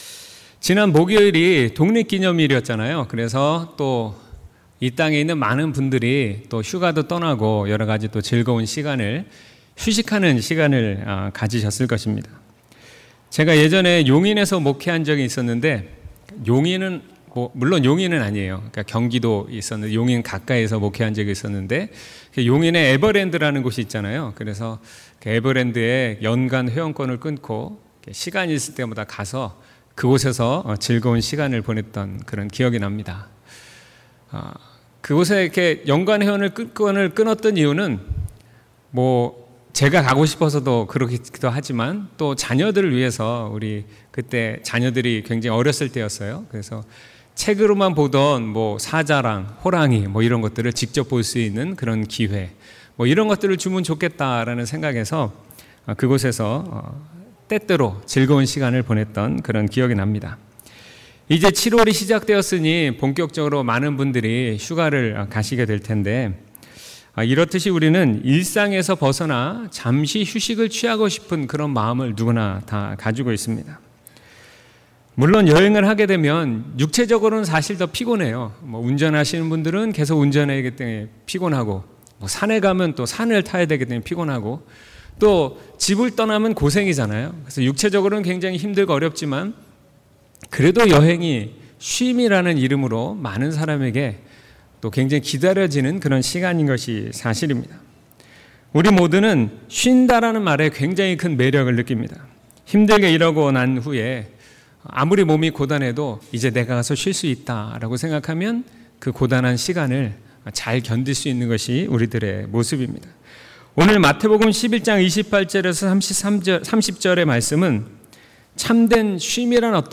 2019년 7월 7일 주일설교/ 참된 쉼/마11:28-30